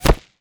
bullet_impact_dirt_04.wav